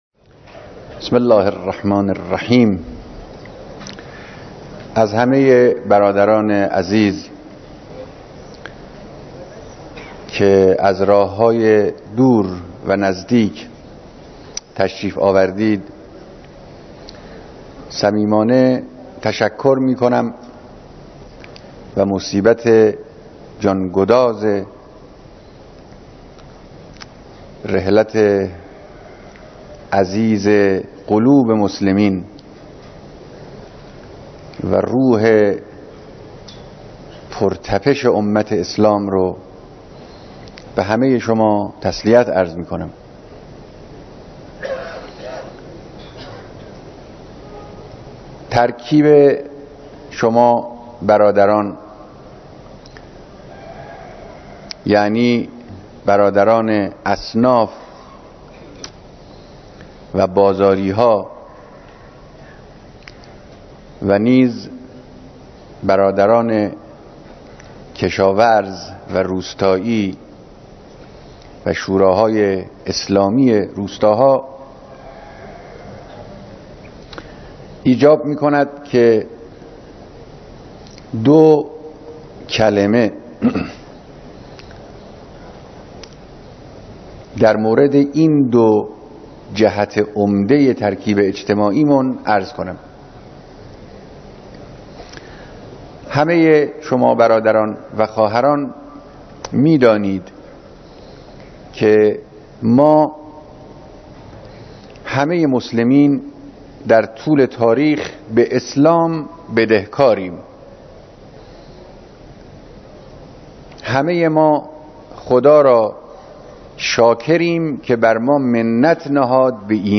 بیانات رهبر انقلاب در مراسم بیعت جمعی از اصناف تهران و روستائیان کشور